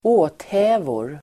Ladda ner uttalet
åthävor substantiv, manners , behaviour Uttal: [²'å:thä:vor] Användning: plural Definition: (överdrivet) sätt att uppföra sig Exempel: han talade lugnt och utan åthävor (he talked quietly and without fuss)